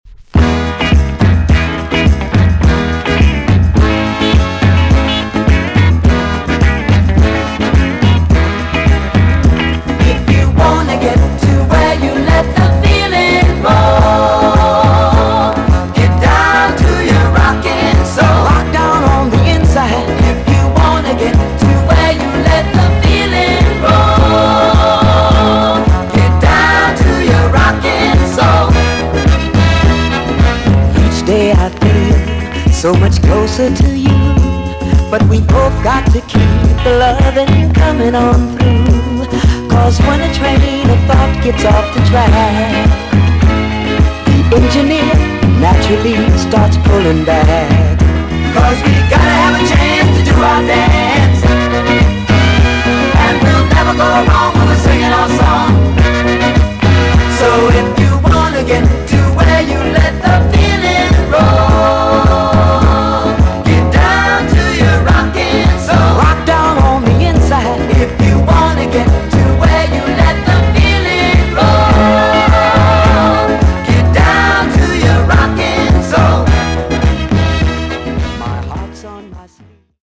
EX- 音はキレイです。
NICE DISCO TUNE!!